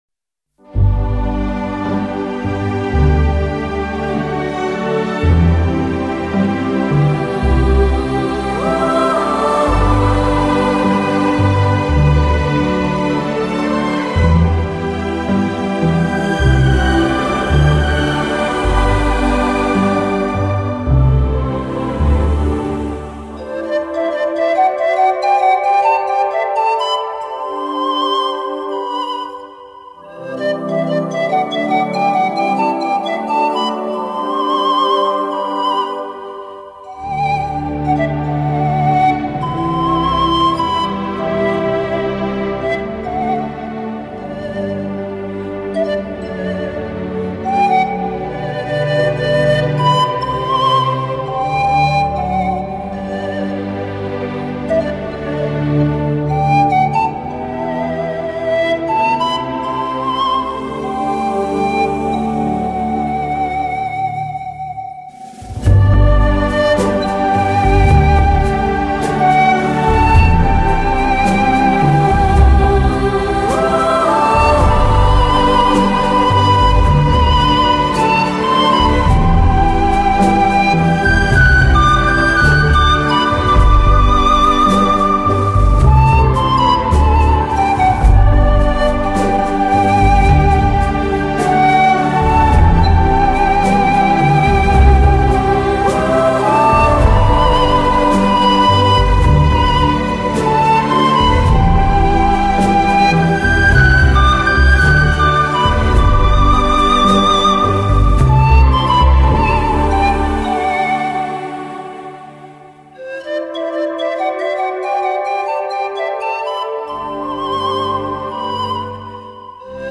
音色迷人的古老乐器，具有魔力的美妙音符，为你营造一个悠远宁静的、梦的氛围……